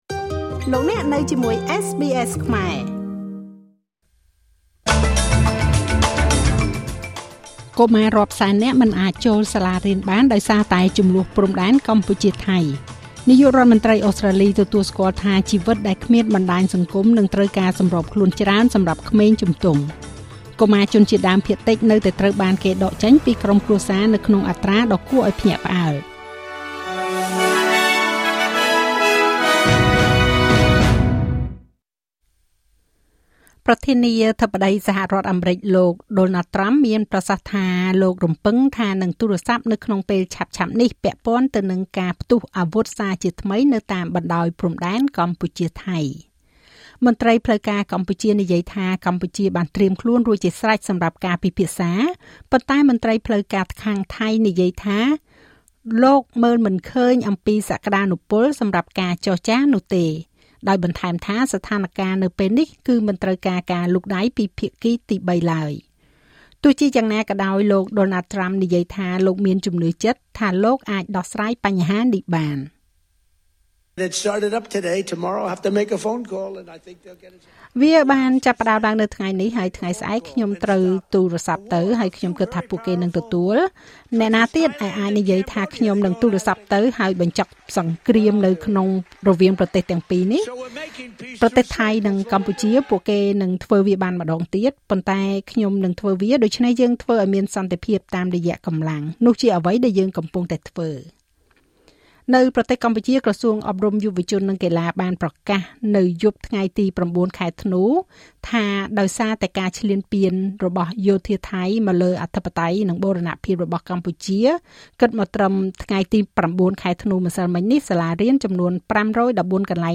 នាទីព័ត៌មានរបស់SBSខ្មែរ សម្រាប់ថ្ងៃពុធ ទី១០ ខែធ្នូ ឆ្នាំ២០២៥